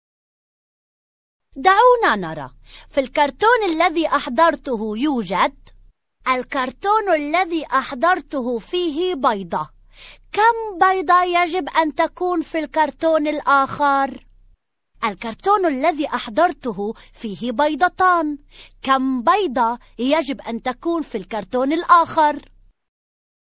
Arabic – female – AK Studio